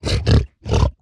sounds / mob / hoglin / idle5.ogg